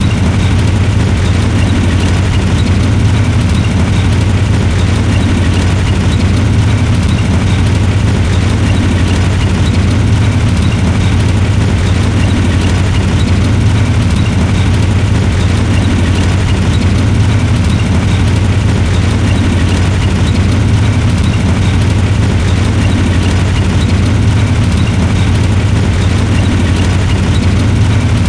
Tiếng xe Tăng chạy
Thể loại: Đánh nhau, vũ khí
Description: Tiếng động cơ đều đều, tiếng chuyển động nhịp nhàng của bánh xích xe tăng, mô tả chân thực tiếng của động cơ xe tăng, xình xịch di chuyển trên địa hình. Hiệu ứng âm thanh này mô tả chân thực tiếng xe tăng chạy, là một trong những âm thanh dấu hiệu của chiến tranh.
tieng-xe-tang-chay-www_tiengdong_com.mp3